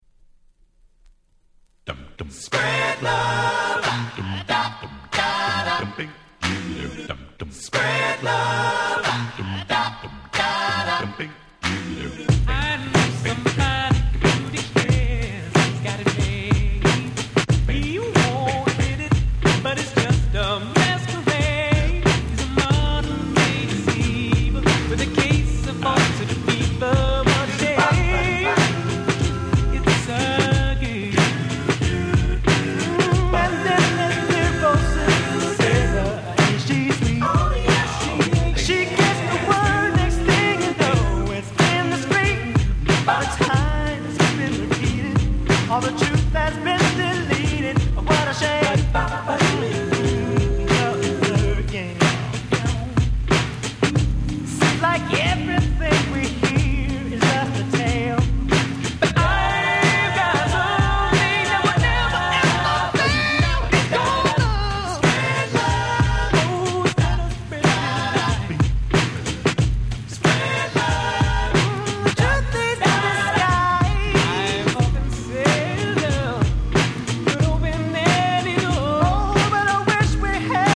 勿論こちらもBeatはインピーチ！！